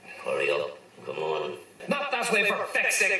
Instead of a counter, I think the same feelings of stress and urgency could be achieved by installing to the traffic lights a voiceover of Fathers Fitzgerald and Ted speaking on the Tannoy in Ireland’s largest lingerie section from A Christmassy Ted: